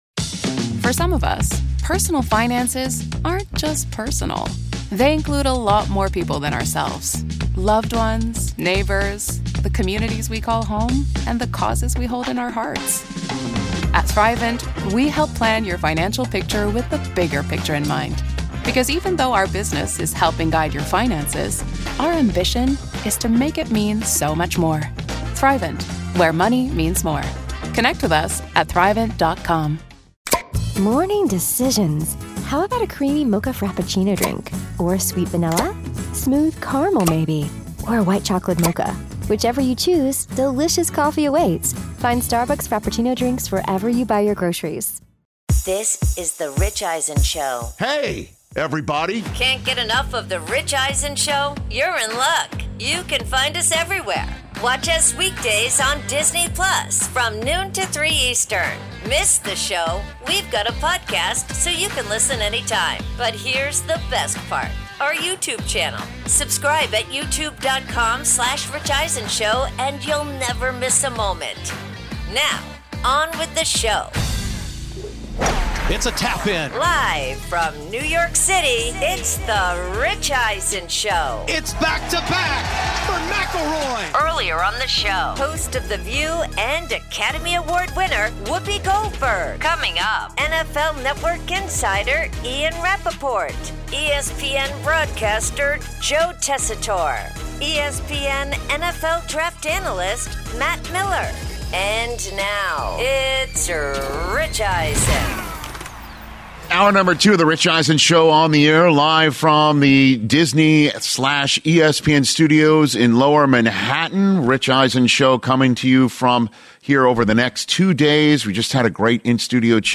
Hour 2: Overreaction Monday, plus NFL Insider Ian Rapoport In-Studio